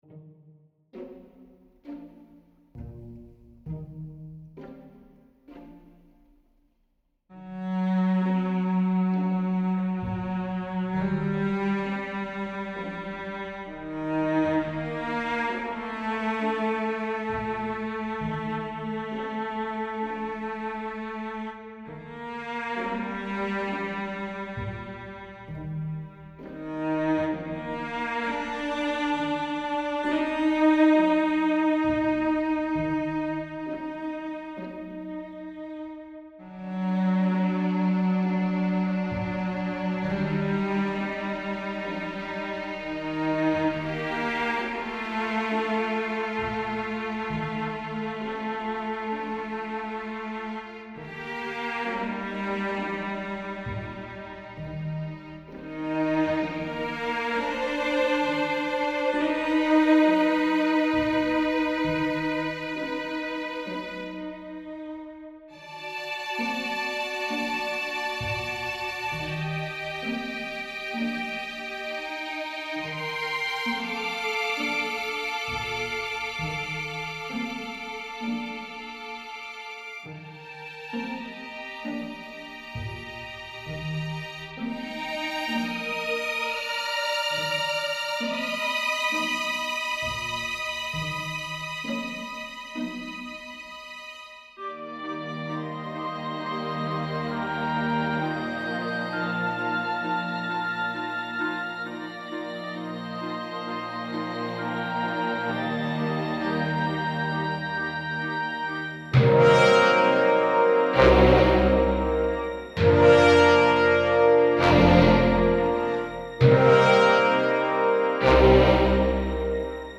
(Orchestra with Solo Cello)